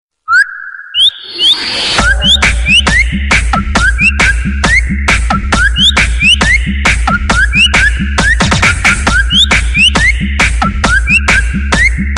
wake-up-tone_24662.mp3